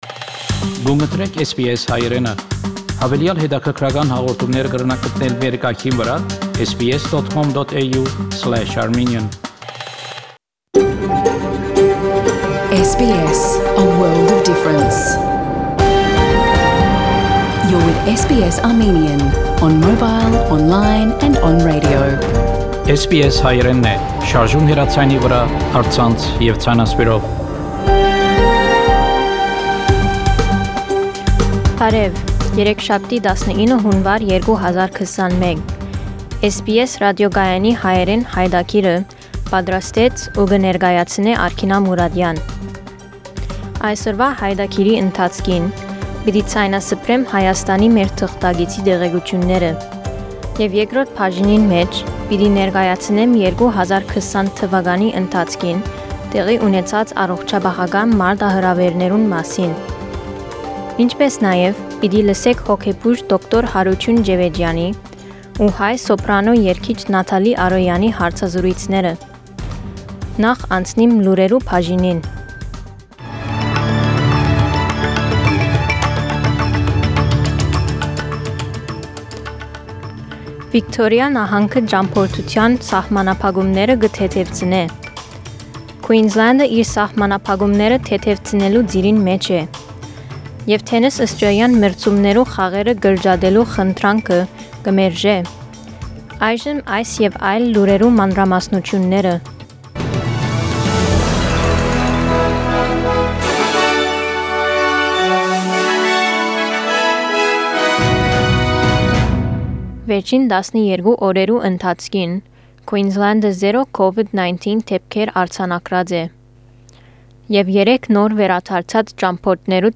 SBS Armenian news bulletin– 19 January 2021
SBS Armenian news bulletin from 19 January 2021 program.